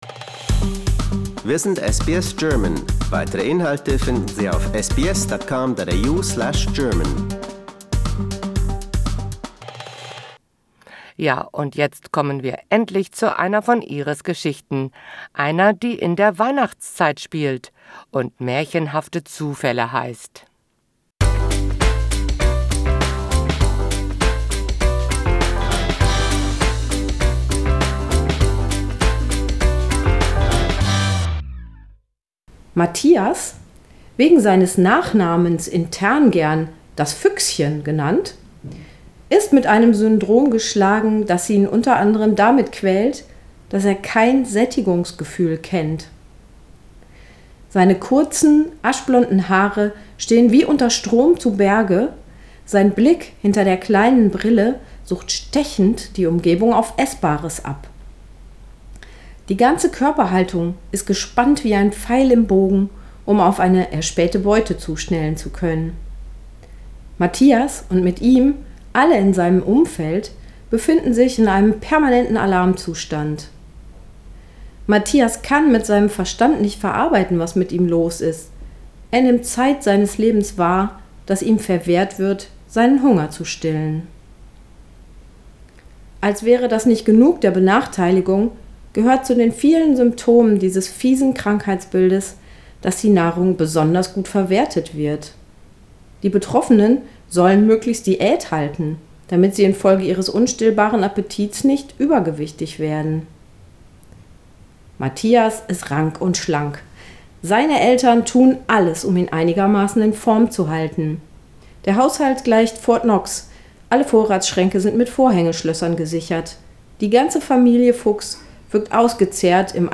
Autorenlesung